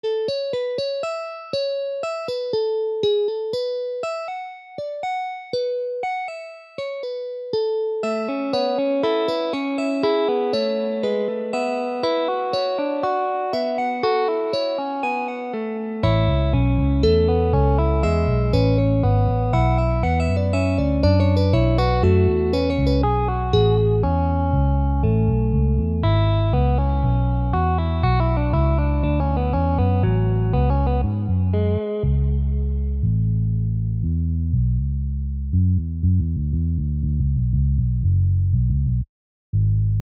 cannon-writing.mp3